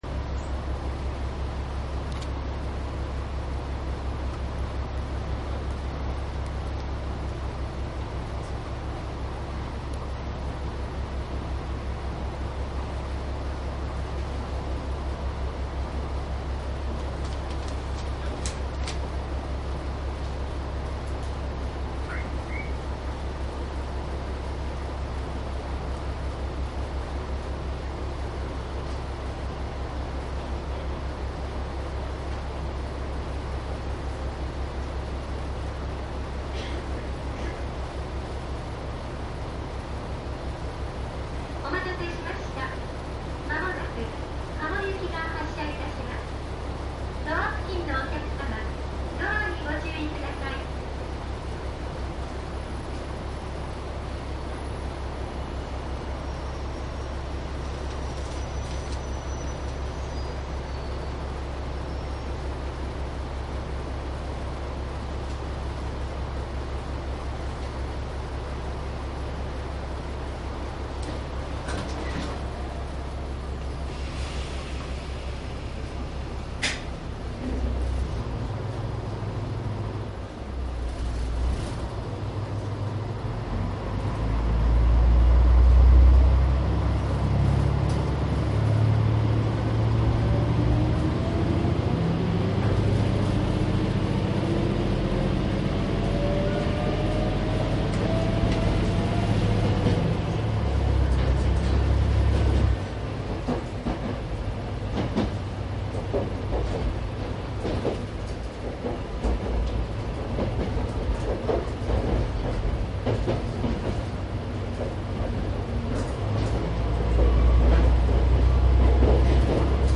関西本線 キハ120走行音 ＣＤ  ♪♪
商品説明  ♪内容はJR関西本線でキハ120を録音したものになります。
■【普通】亀山→加茂 キハ120－10＜DATE01-1-2＞
DATの通常SPモードで録音（マイクＥＣＭ959）で、これを編集ソフトでＣＤに焼いたものです。